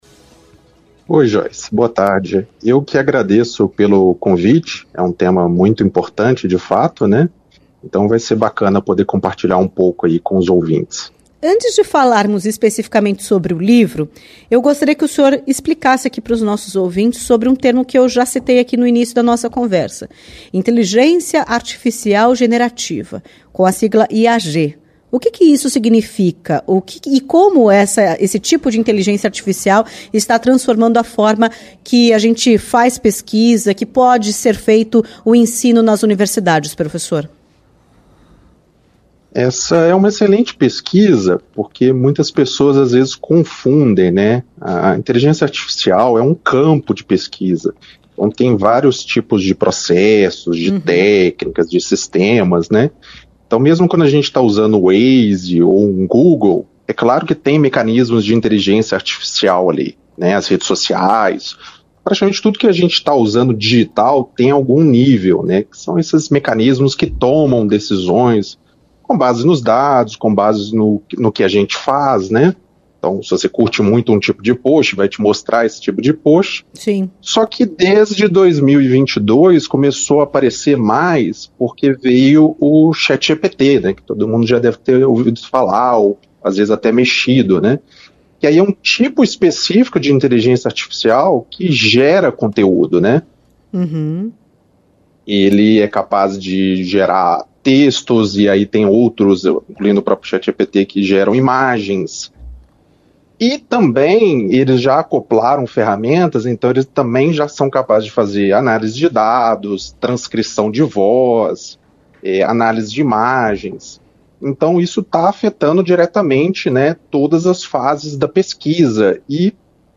Entrevista-0901.mp3